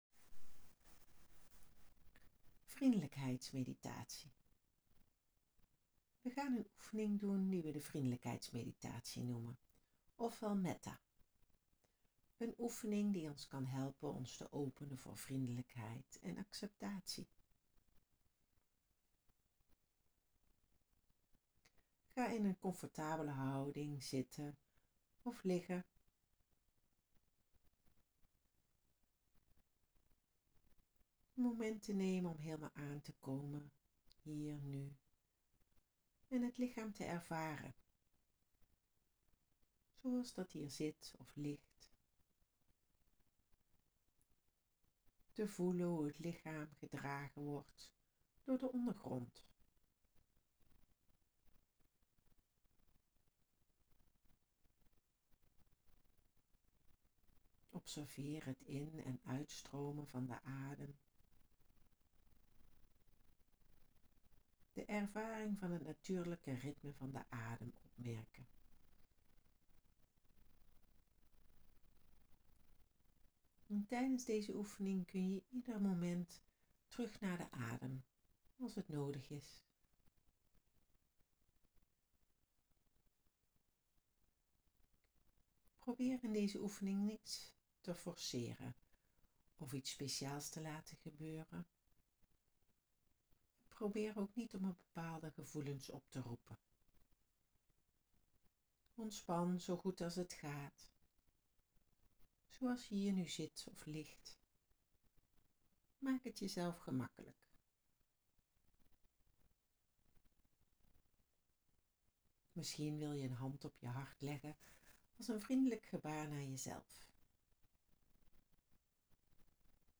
Mindfulness oefeningen
Vriendelijkheidsmeditatie.wav